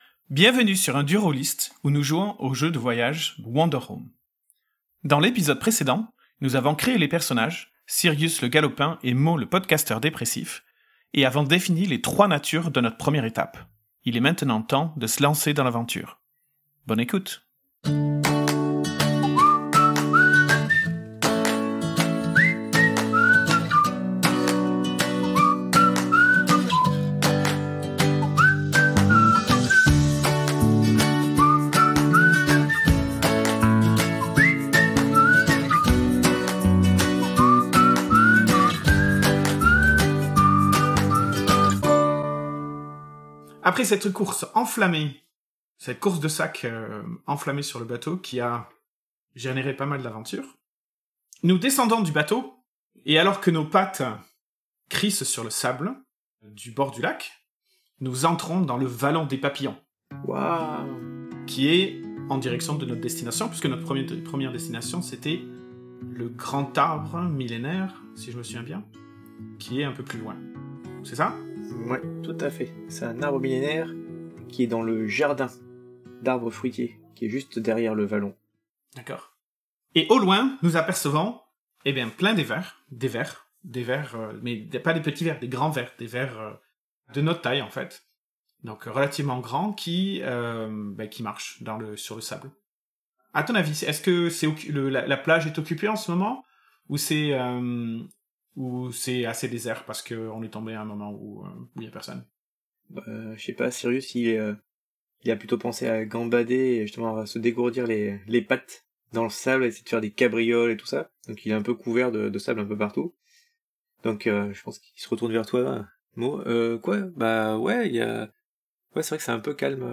Ambient Acoustic Guitar Instrumental Background Music
The sound of a ladybug taking off